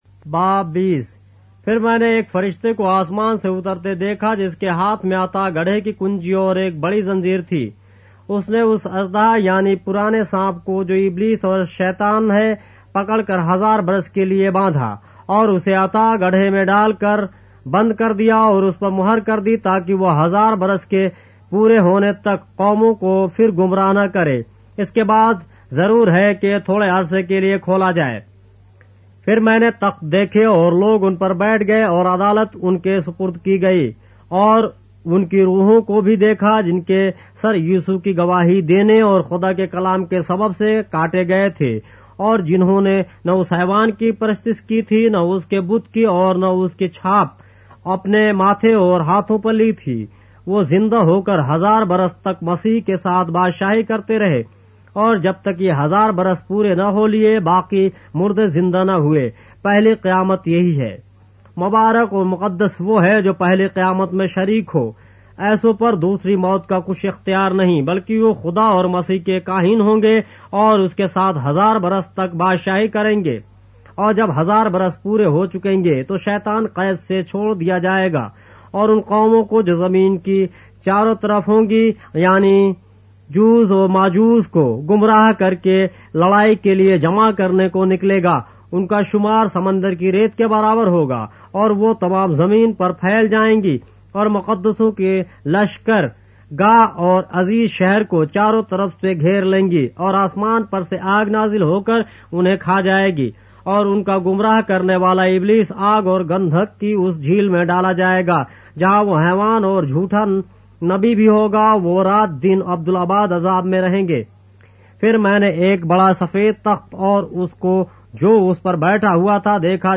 اردو بائبل کے باب - آڈیو روایت کے ساتھ - Revelation, chapter 20 of the Holy Bible in Urdu